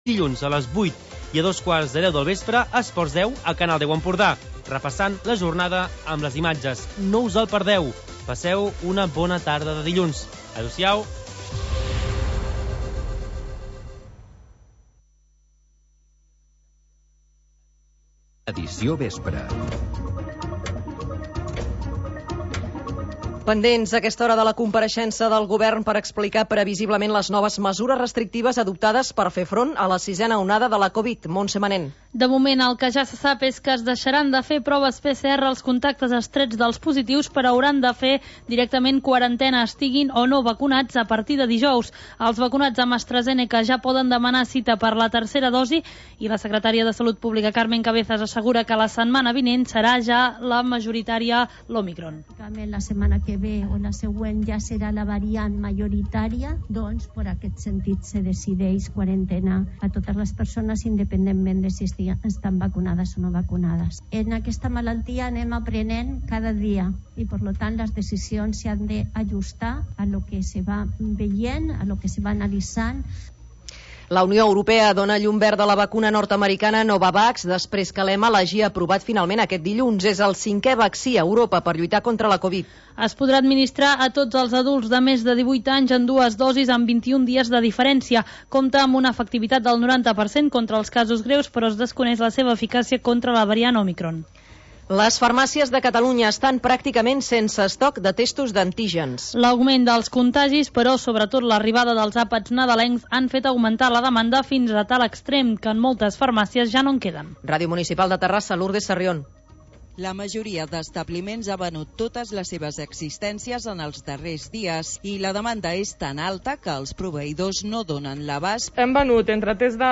Noticiari d'informació territorial